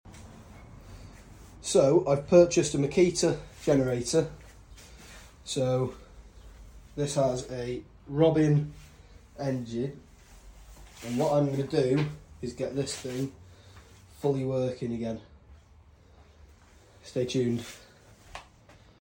Genorator pt 1 sound effects free download